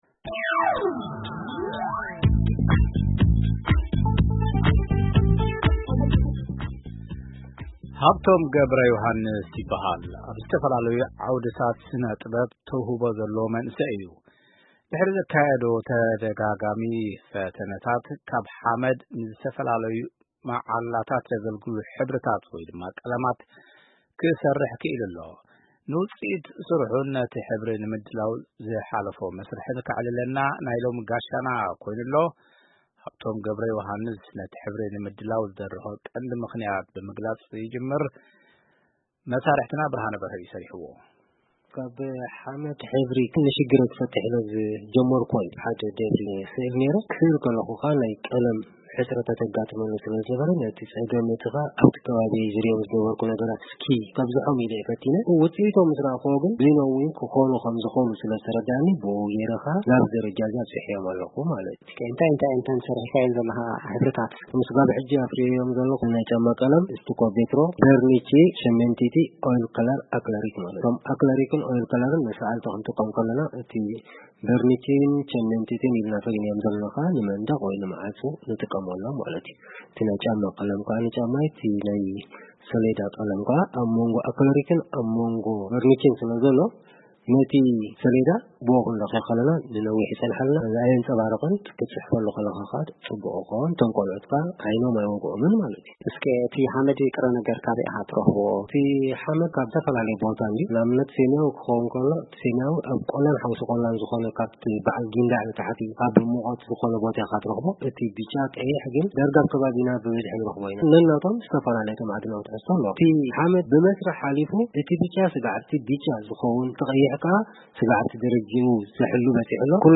ድሕሪ ዘካየዶ ተደጋጋሚ ፈተነታት ካብ ሓመድ ንዝተፈላለዩ መዓላታት ዘገልግሉ ሕብርታት(ቀለማት)ከሰርሕ ክኢሉ’ሎ። ንውጽኢት ስርሑን ነቲ ሕብሪ ንምድላው ዝሓለፎ መስርሕን ከዕልለና ናይ ሎሚ ጋሻና ኮይኑ’ሎ።